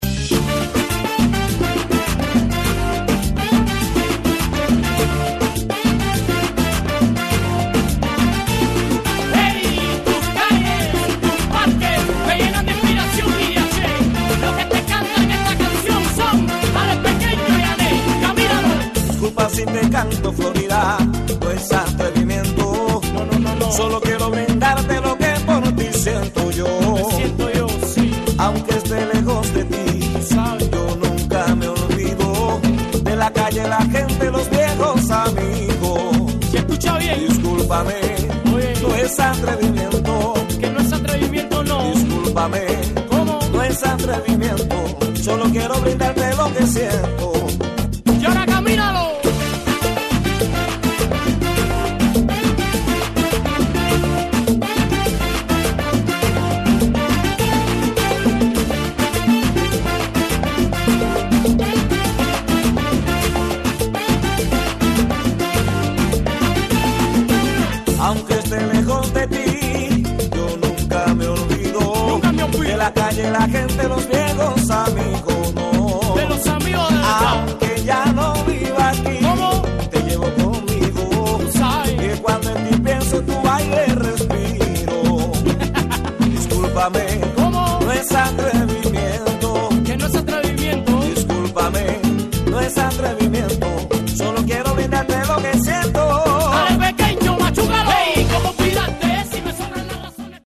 • Latin/Salsa/Reggae